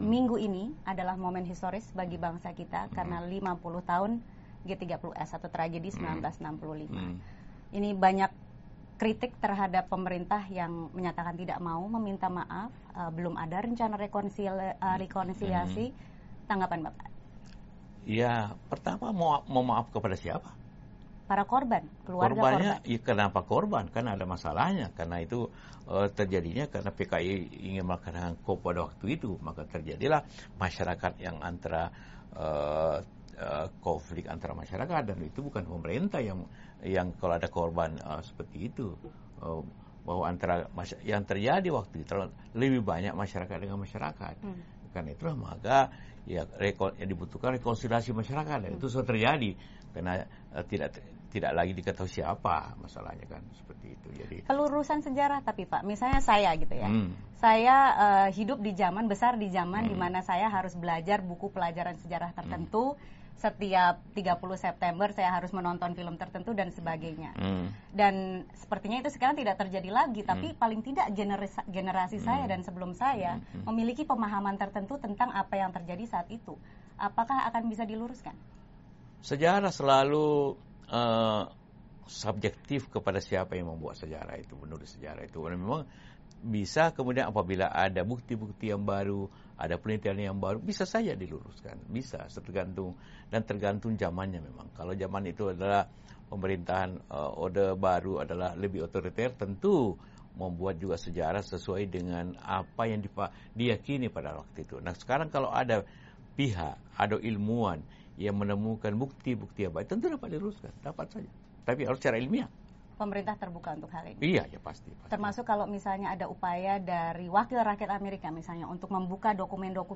Wawancara VOA dengan Wapres Jusuf Kalla di Markas Besar PBB, New York: Tragedi 1965